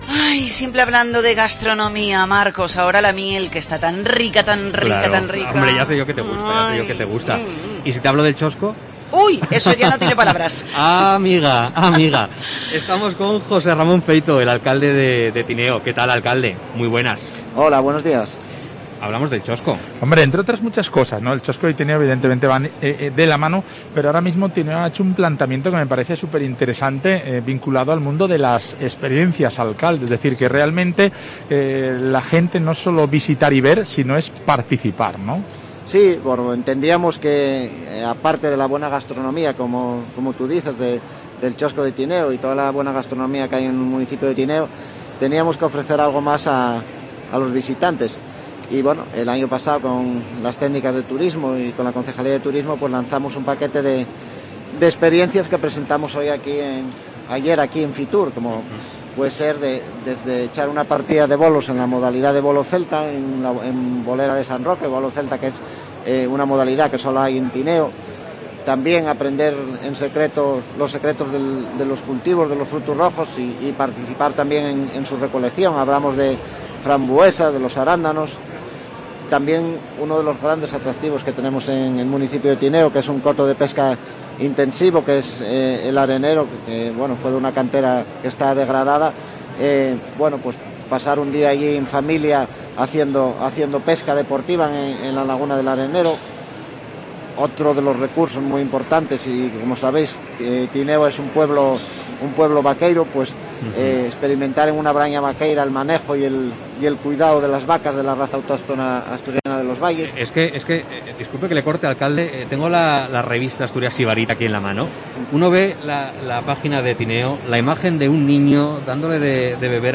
El alcalde del concejo, José Ramón Feito, ha participado en el programa especial de COPE Asturias desde IFEMA Madrid con motivo de la celebración de Fitur
Fitur 2022: Entrevista a José Ramón Feito, alcalde de Tineo